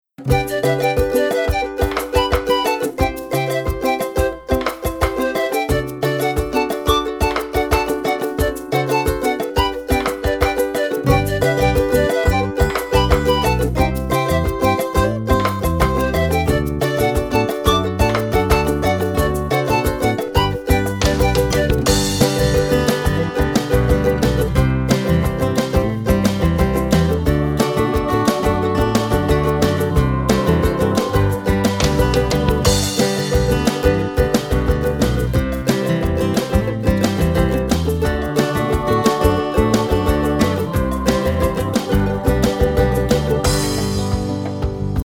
Listen to a sample of the instrumental version.
Downloadable Instrumental Track